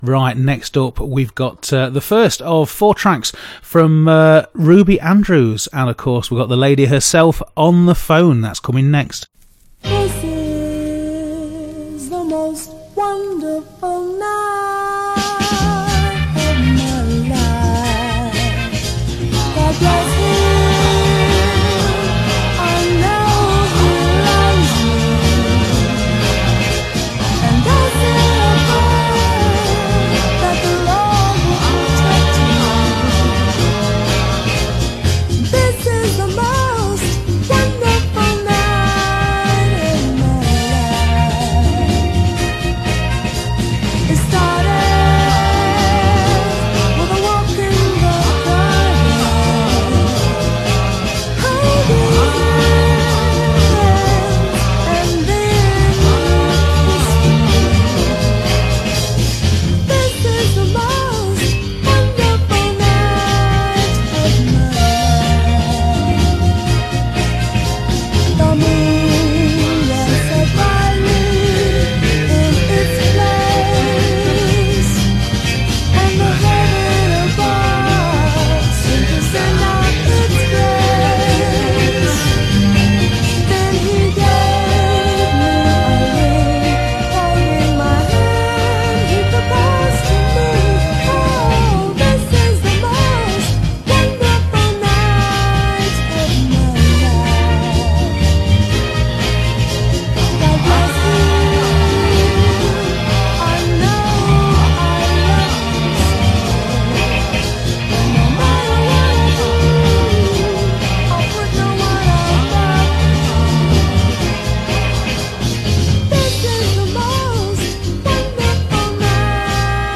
Clink the link below to hear the interview dated 21st May 2018